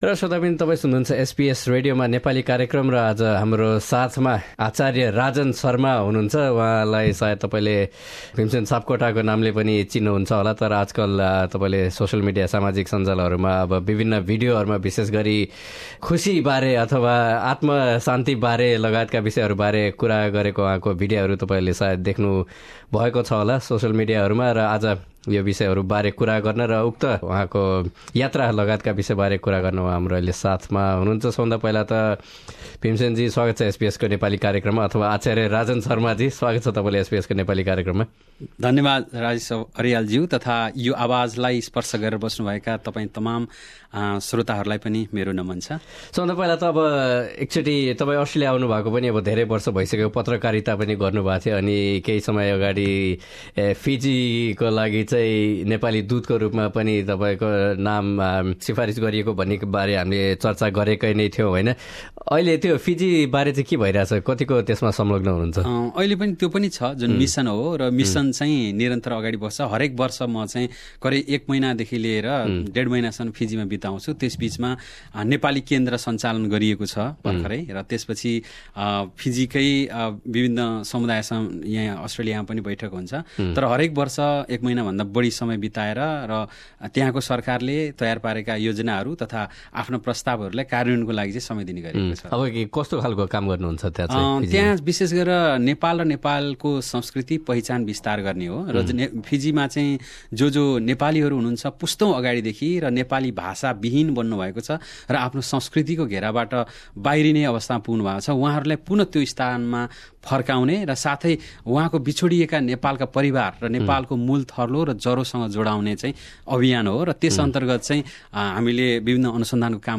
उनले एसबीएस नेपालीसँग गरेको कुराकानी।